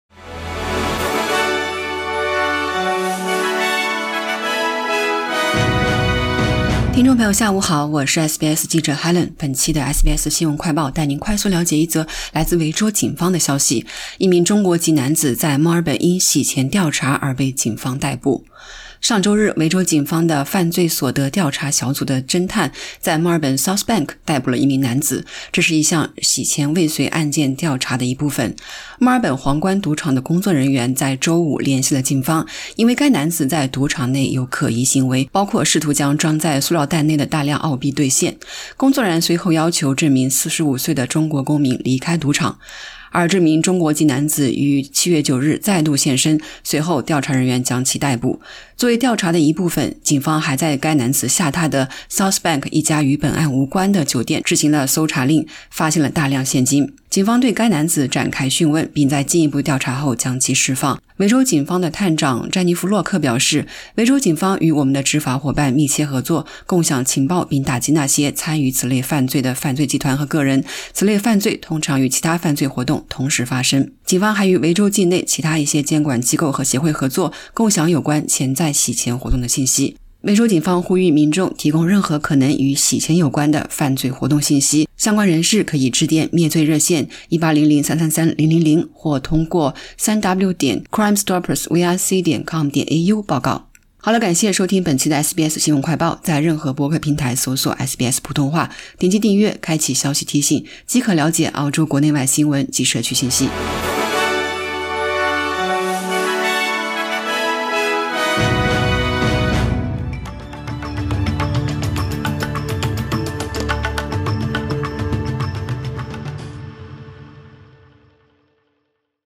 SBS News Flash in Mandarin: Man arrested as part of money laundering investigation Source: AAP